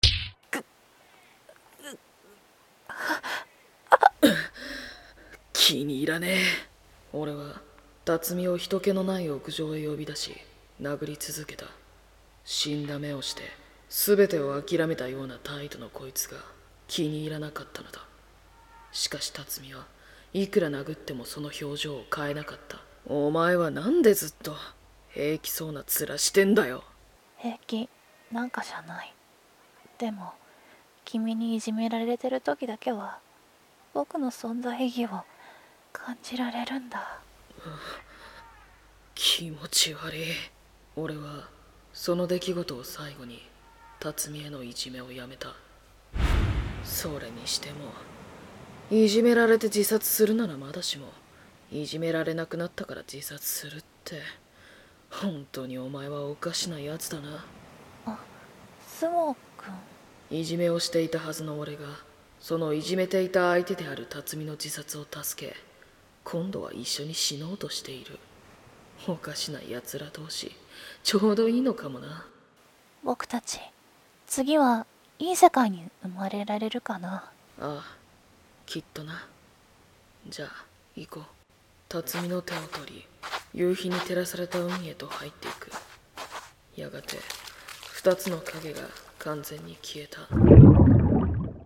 【BL声劇】泡影に消える 後半②